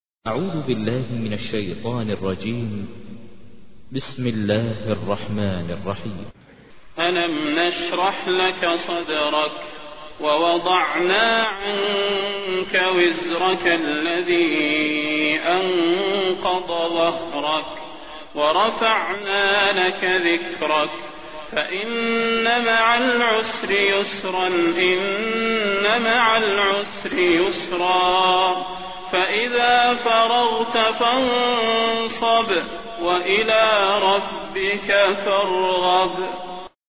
تحميل : 94. سورة الشرح / القارئ ماهر المعيقلي / القرآن الكريم / موقع يا حسين